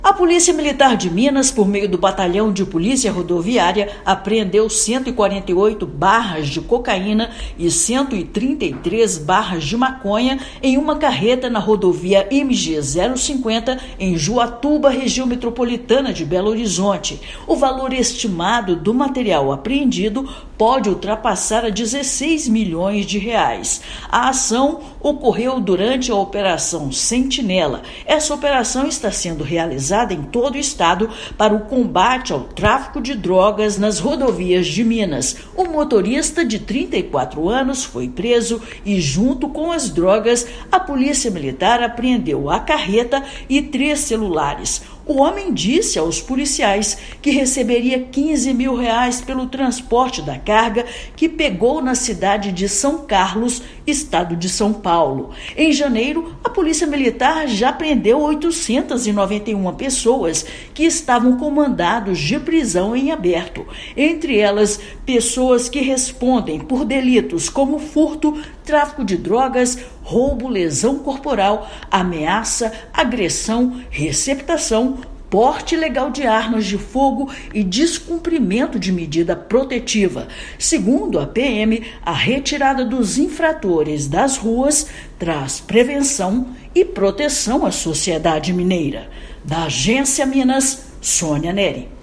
Operação Sentinela aborda carreta na rodovia MG-050 e descobre 148 barras de cocaína e 133 barras de maconha. Ouça matéria de rádio.